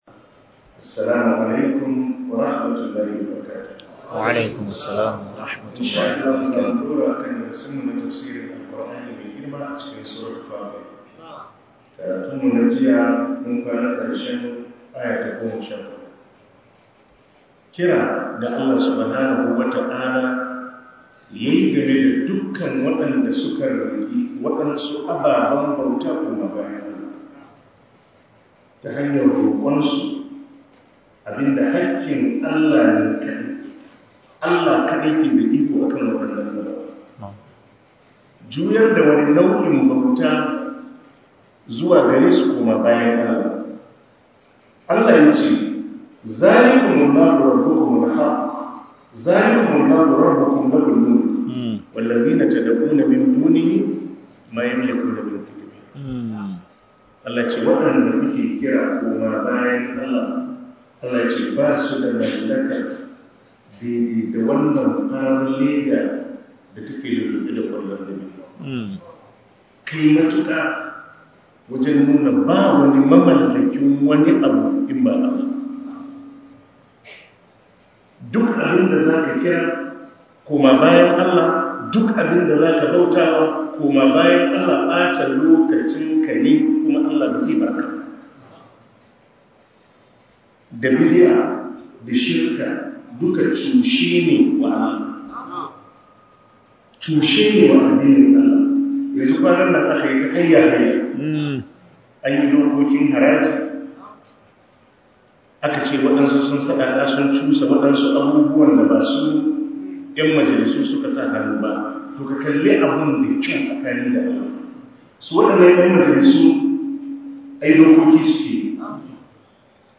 ← Back to Audio Lectures 08 Ramadan Tafsir Copied!